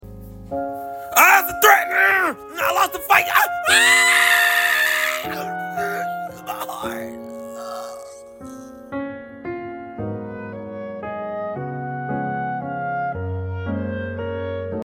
Laugh Out Loud: Hilarious Animal sound effects free download